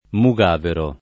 mug#vero] s. m. (stor.) — rare le forme mogavero [mog#vero] e, con l’art. ar., almogavero [almog#vero] — sim. i cogn. Mugavero e Mogavero (anche Moavero [mo#vero] e Mocavero [mok#vero])